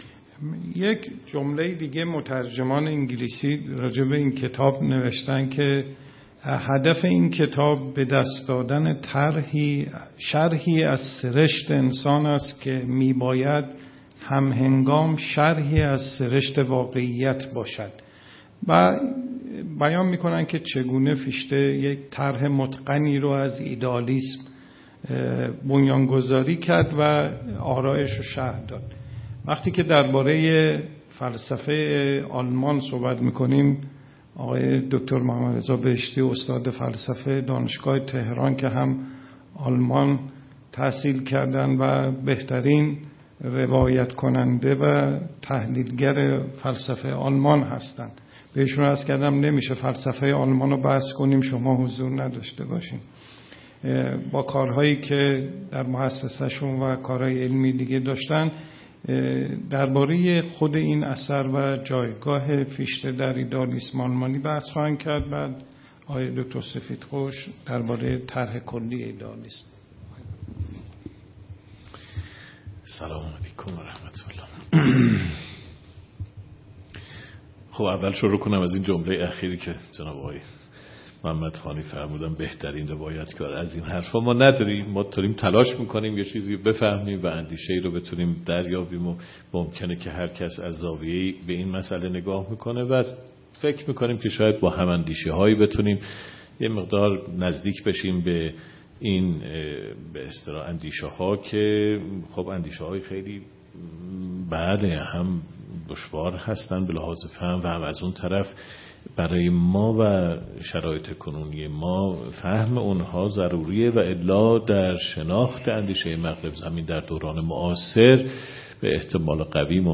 سخنرانی
در نشست نقد و بررسی کتاب «بنیاد آموزه‌ فراگیر دانش» است که در مؤسسه‌ی شهر کتاب برگزار شد.